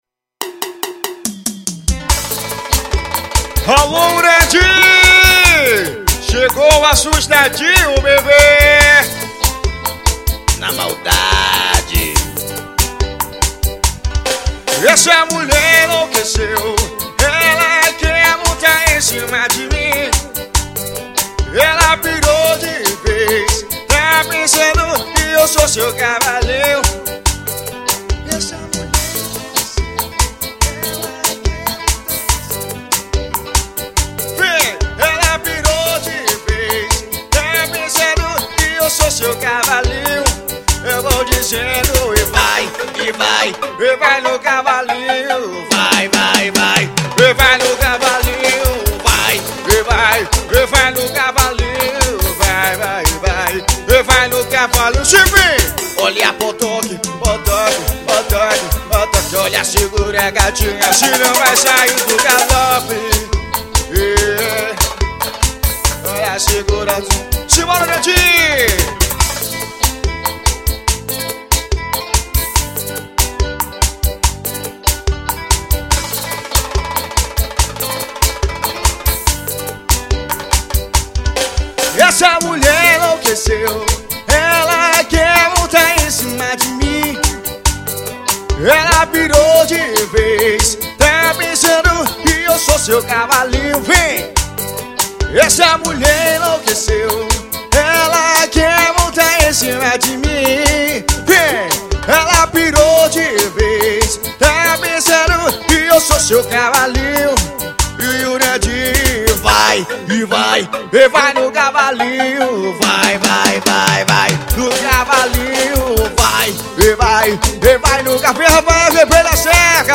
arrocha.